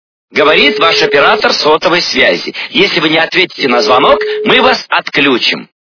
» Звуки » Люди фразы » Михаил Галустян - Говорит ваш оператор сотовой связи!
При прослушивании Михаил Галустян - Говорит ваш оператор сотовой связи! качество понижено и присутствуют гудки.